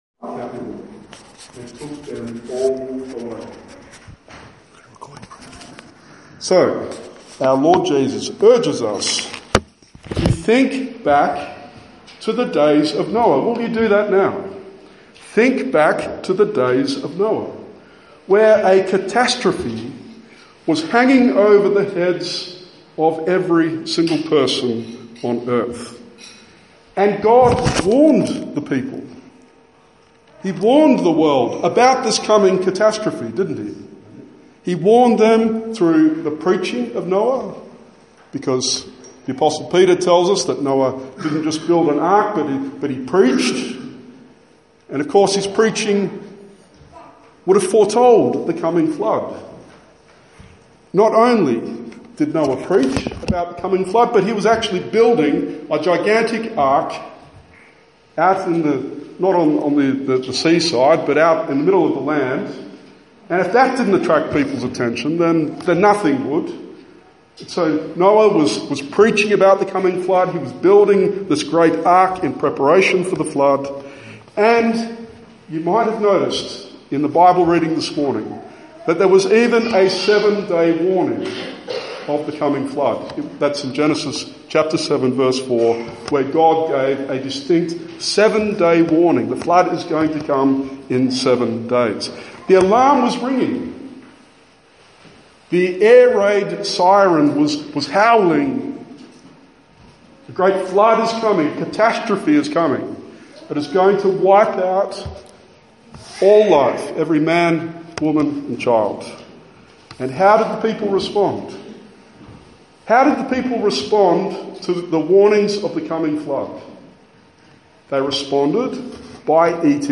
Matthew 24:37-44 Sermon